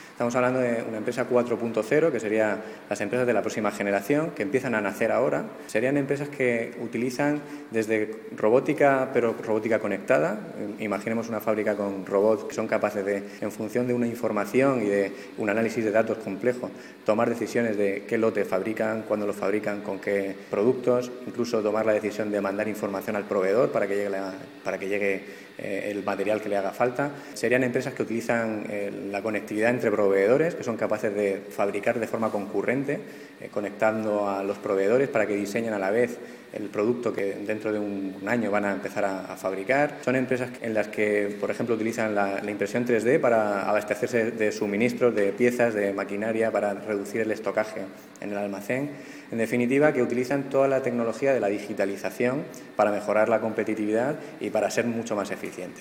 Declaraciones del director del Instituto de Fomento, Javier Celdrán – ‘Jornada Murcia Industria 4.0’. II